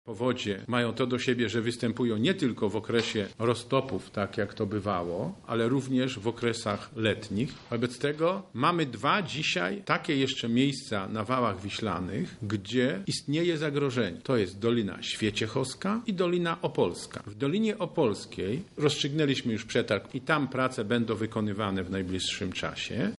– mówi marszałek Sławomir Sosnowski.